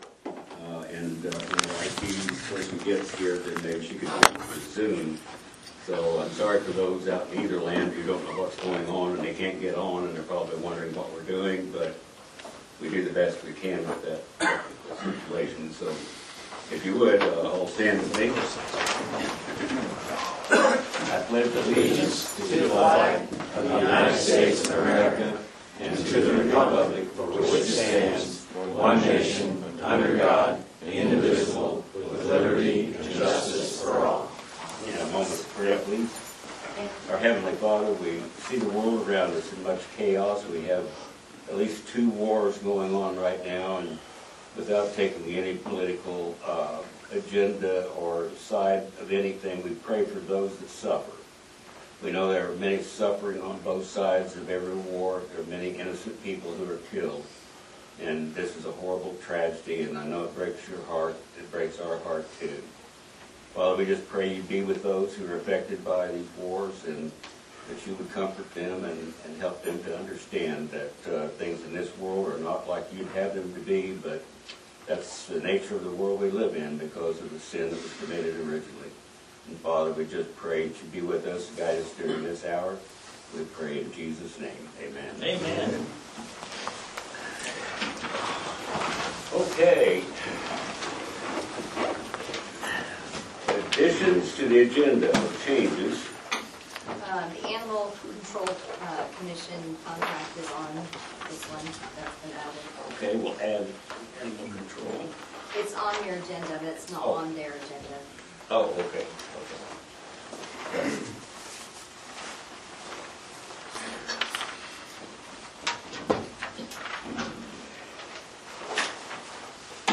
Excellent presentation and discussion regarding the conditions and policy regarding gravel roads.
audio-commissioner-meeting-dec-6-2023.mp3